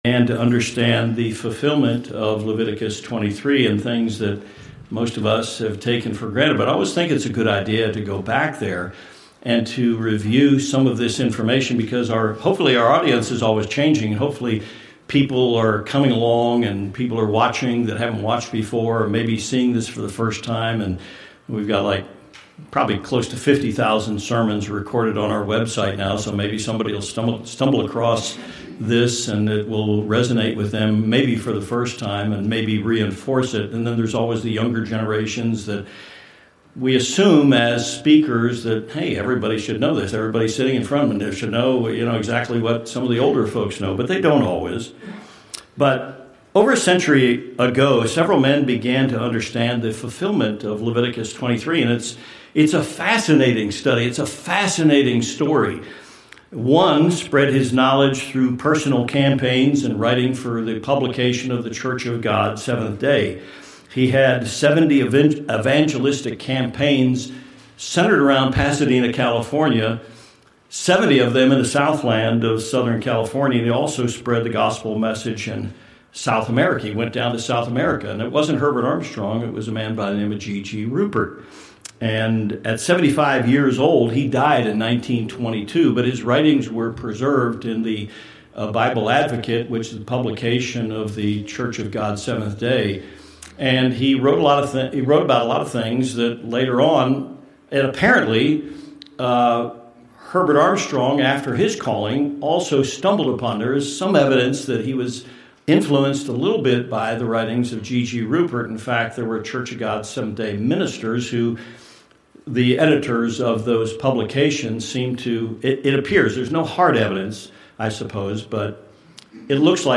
Learn more about the Day of Atonement with this sermon.